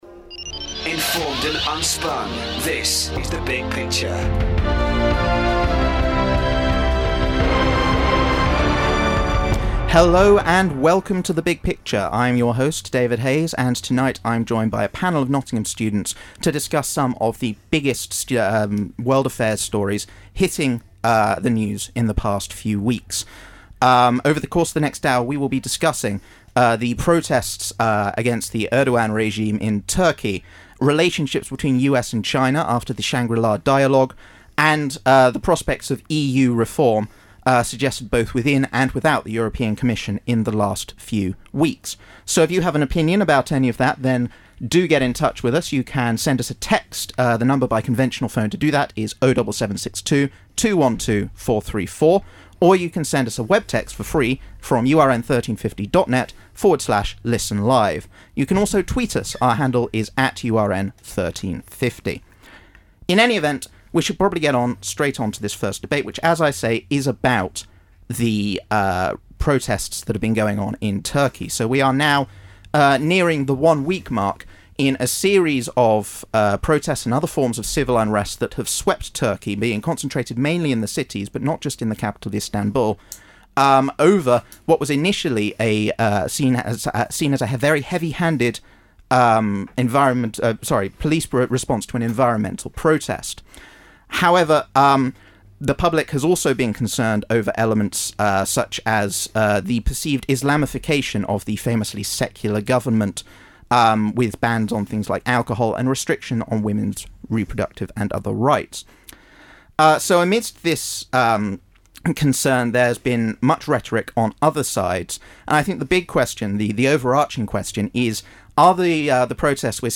Have a listen for some impassioned and topical debate about the direction of global politics